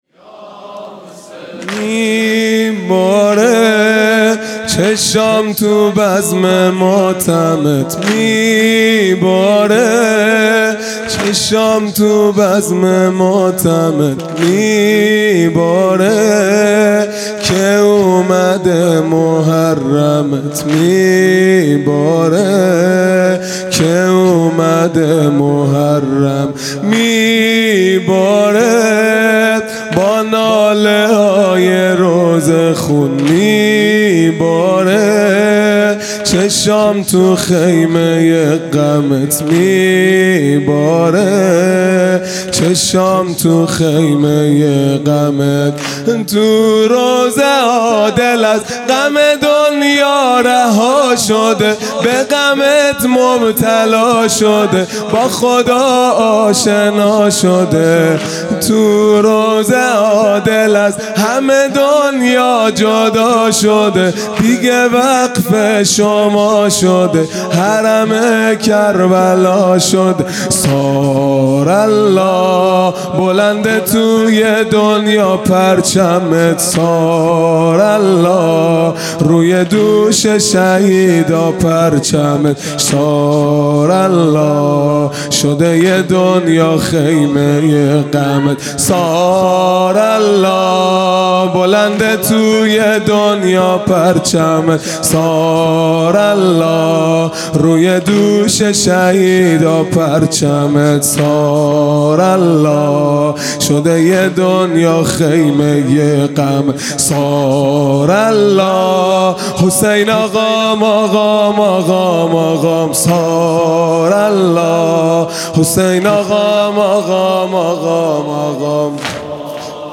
خیمه گاه - هیئت بچه های فاطمه (س) - واحد | میباره چشام تو بزم ماتمت
محرم ۱۴۴۱ |‌ شب پنجم